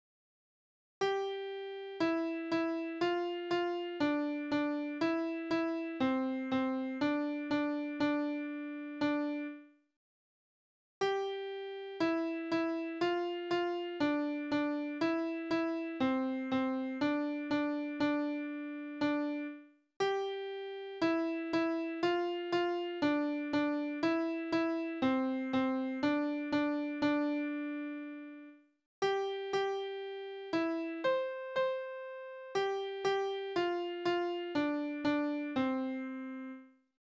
Tonart: C-Dur
Taktart: 4/4
Tonumfang: Oktave